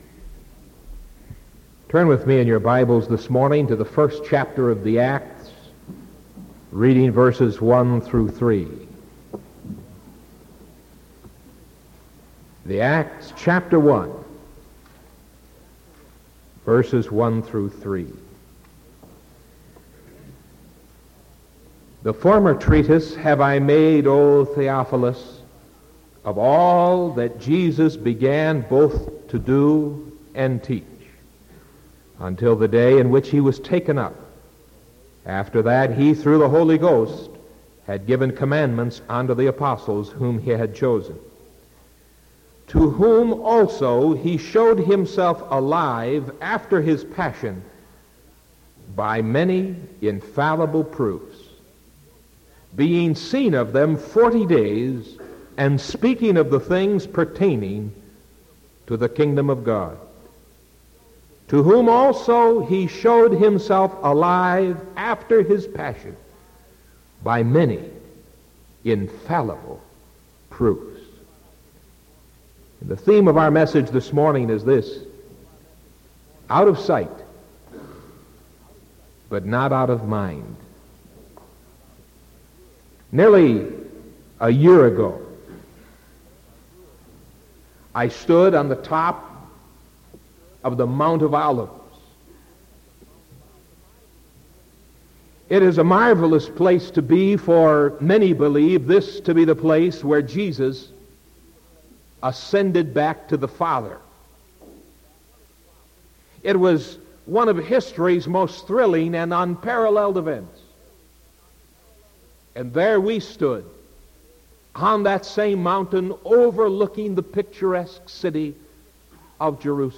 Sermon June 1st 1975 AM